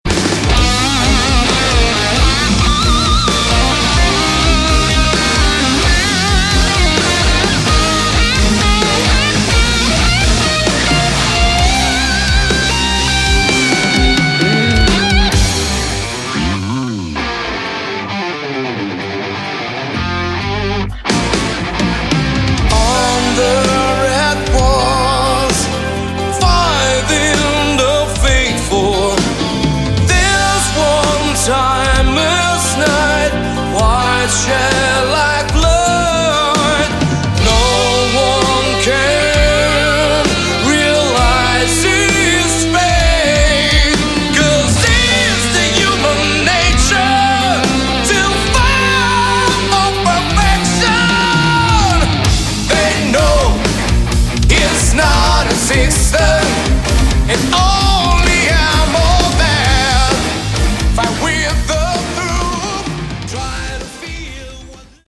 Category: Prog Rock
piano, keyboards
guitars
drums